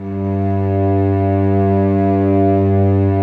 Index of /90_sSampleCDs/Roland LCDP13 String Sections/STR_Vcs II/STR_Vcs6 mf Amb